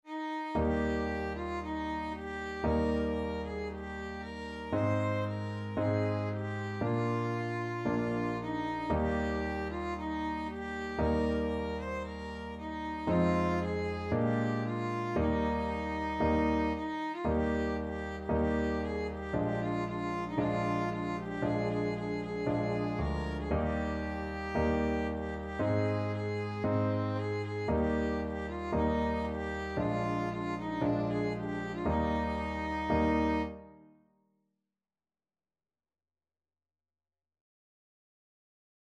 Christian Christian Violin Sheet Music At The Cross (Alas! and did my Savior bleed)
Violin
Eb major (Sounding Pitch) (View more Eb major Music for Violin )
4/4 (View more 4/4 Music)
Traditional (View more Traditional Violin Music)